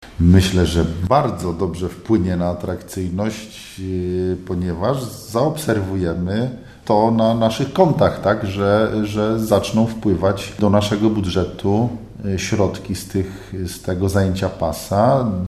Jak mówi burmistrz Sandomierza Marcin Marzec, uruchomienie lokali gastronomicznych oraz ogródków wpłynie na atrakcyjność turystyczną starówki, a co za tym idzie na pojawienie się wpływów finansowych do miejskiego budżetu: